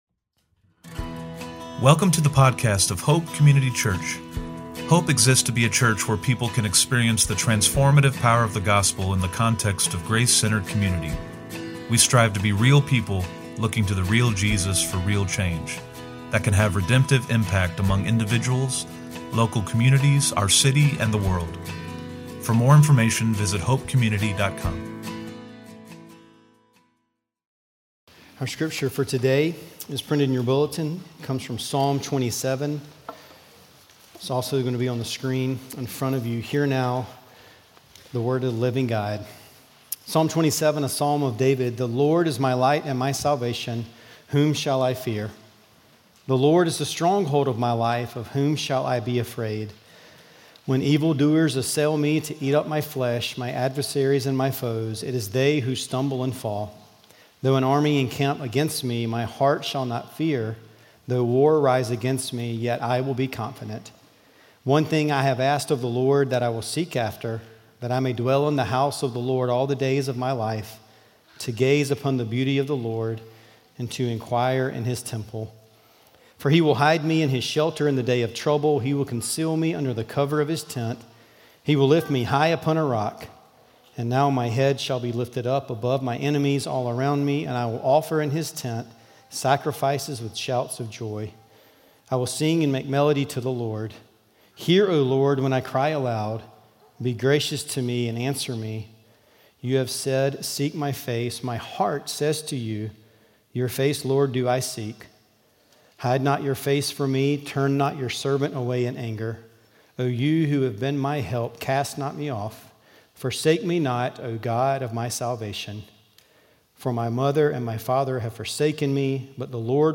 OP-Sermon-9.14.25.mp3